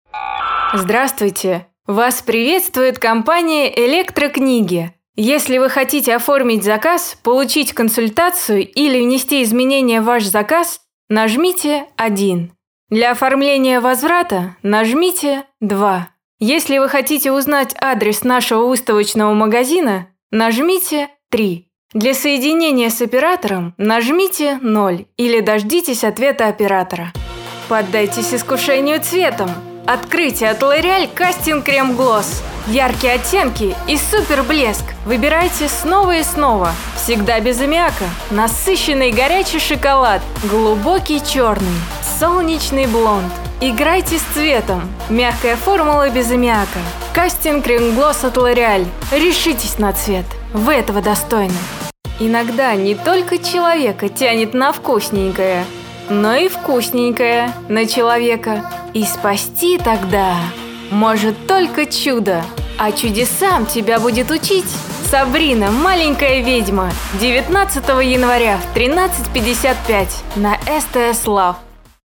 Демо-диктор. Автоответчик/реклама/анонс